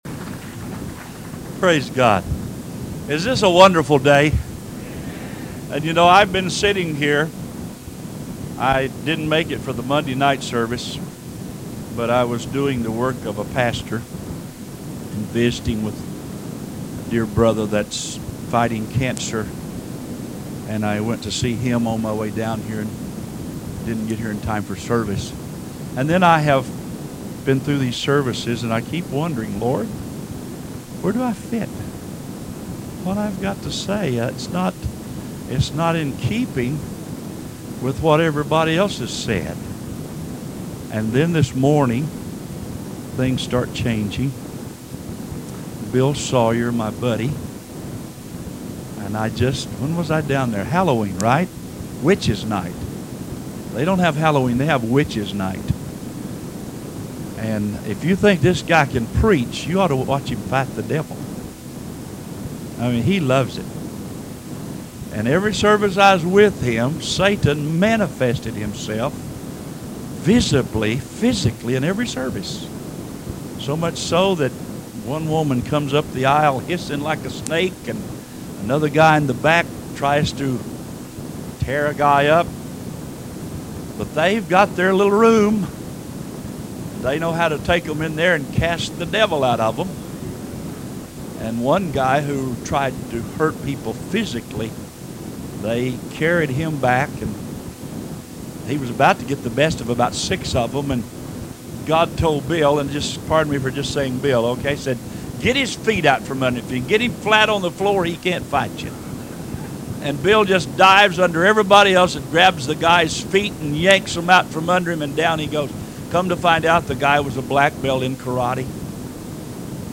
Sermons The Gift of Giving